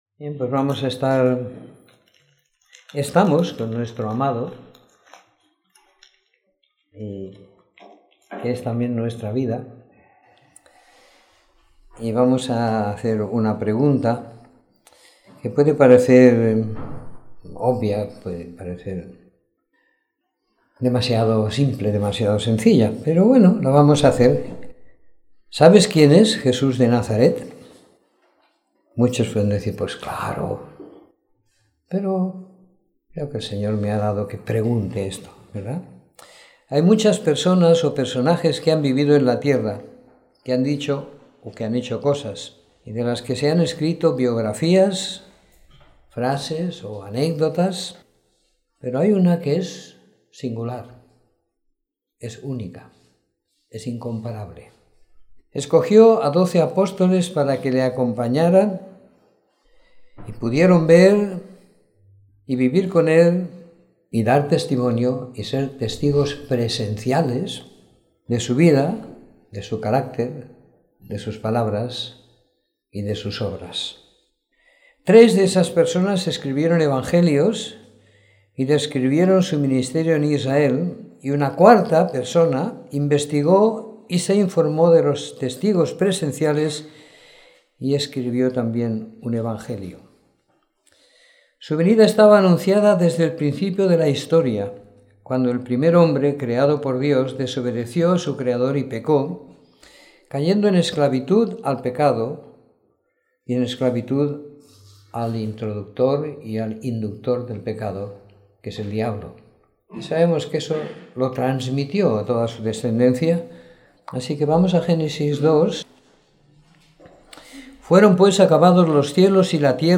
Domingo por la Mañana . 19 de Junio de 2016